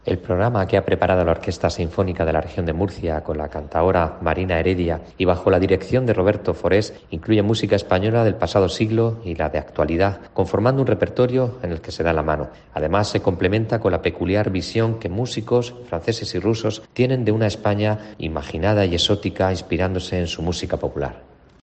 Manuel Cebrián, Director del Instituto de las Industrias Culturales y las Artes